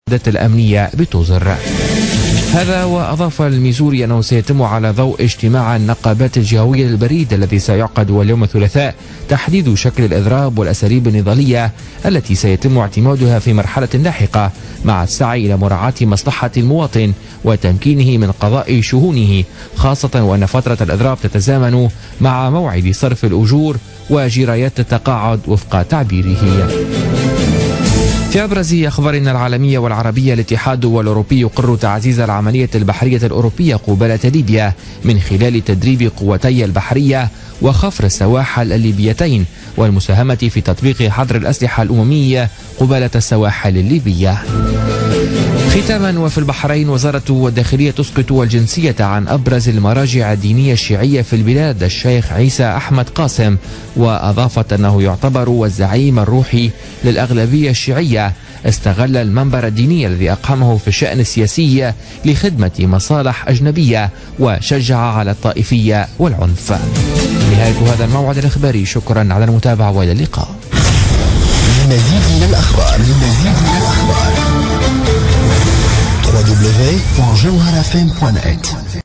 نشرة أخبار منتصف الليل ليوم الثلاثاء 21 جوان 2016